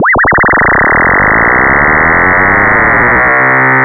SI2 ROAR.wav